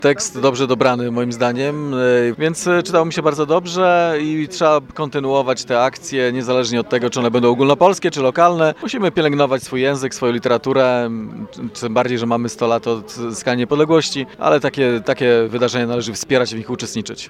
– Warto spędzić sobotnie przedpołudnie z literaturą – mówi Wojciech Iwaszkiewicz, burmistrz Giżycka.
burmistrz.mp3